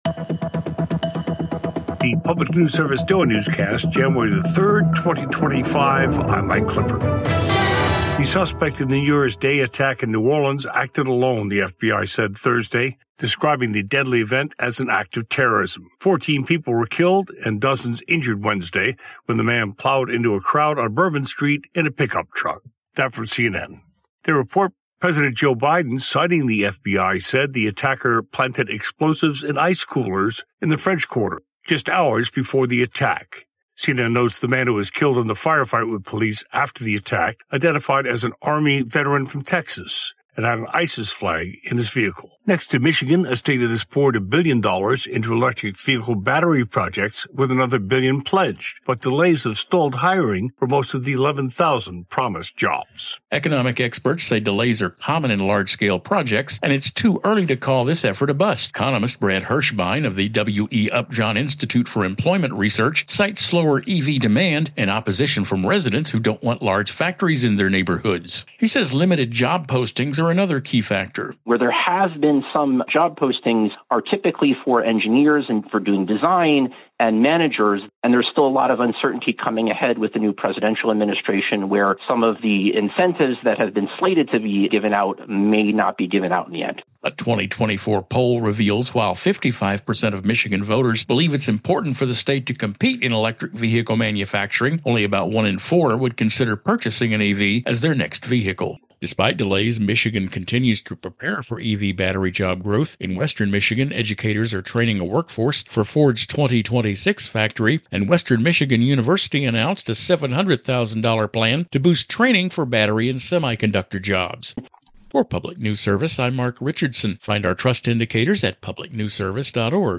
PNS Daily Newscast - January 3, 2025